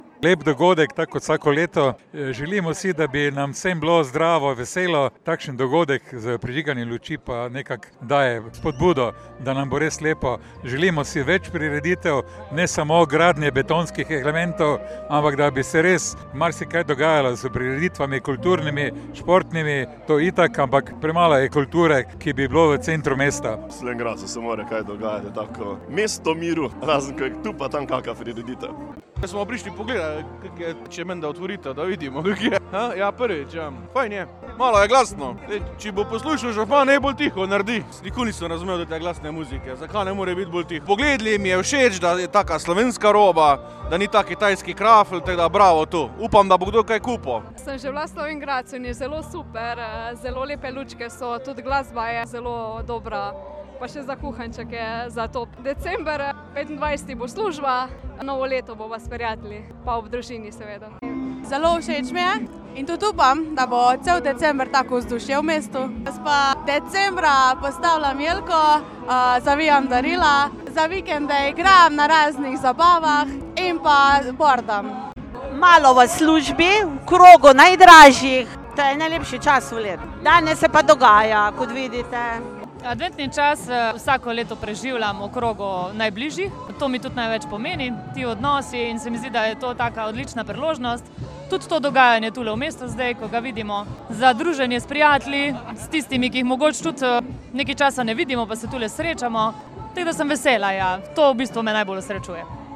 ... tokrat prižig v Slovenj Gradcu